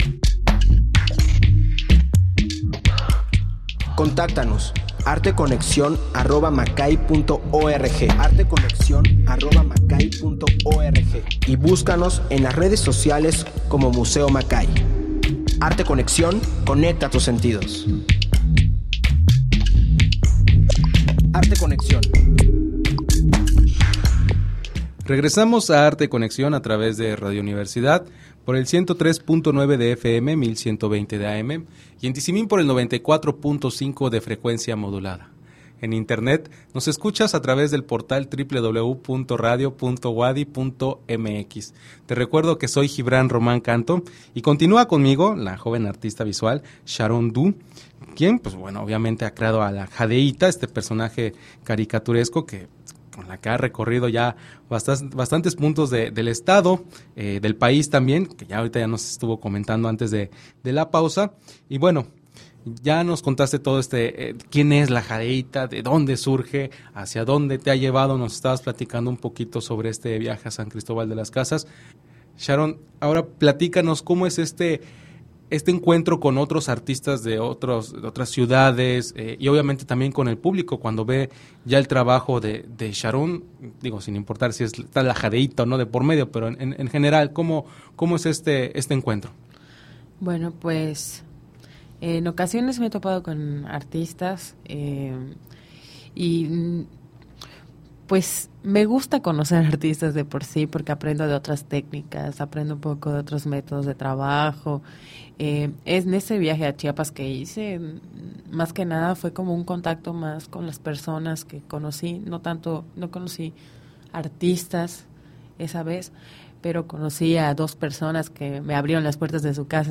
Emisión de Arte Conexión transmitida el 5 de octubre del 2017.